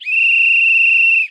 sounds_whistle.ogg